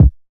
DillaTapeKickin.wav